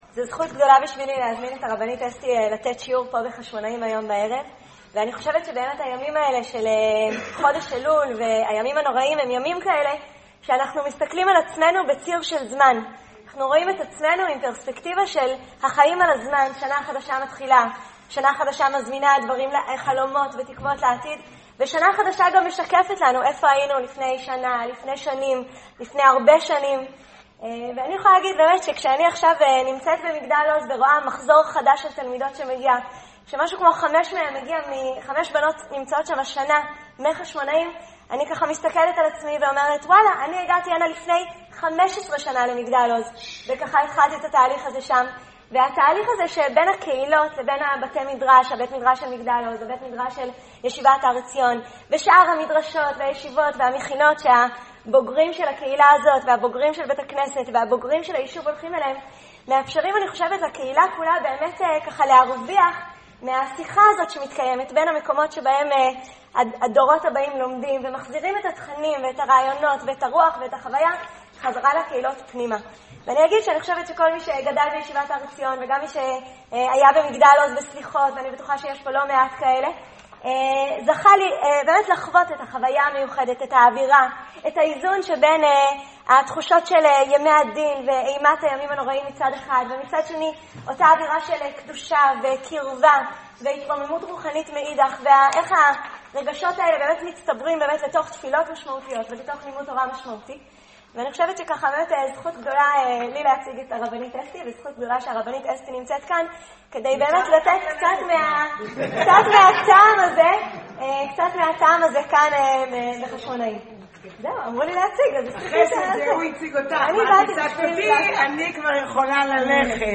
ערב לימוד בבית הכנסת רמת מודיעים ע"ש גלנווד, חשמונאים, בט' באלול תשע"ו.